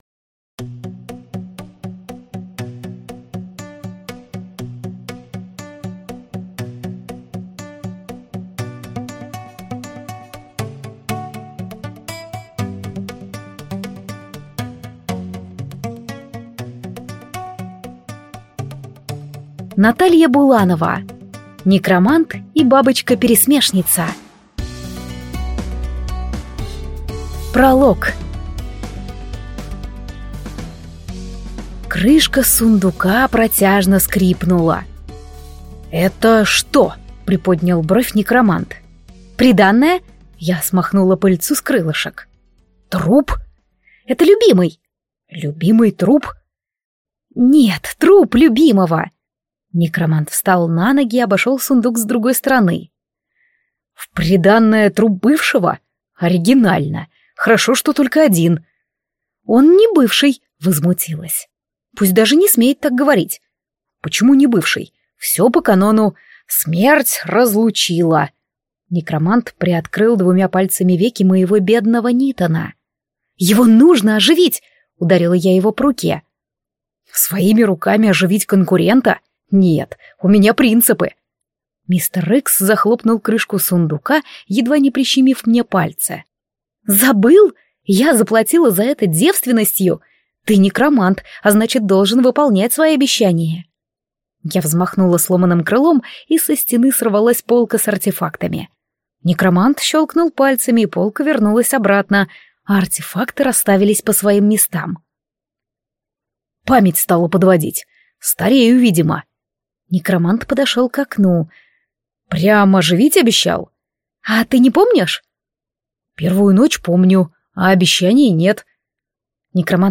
Аудиокнига Некромант и бабочка-пересмешница | Библиотека аудиокниг